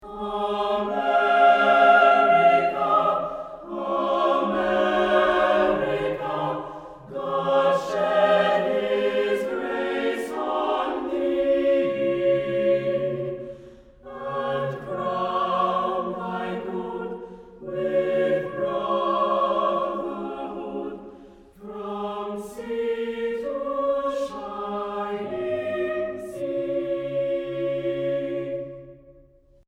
short choral pieces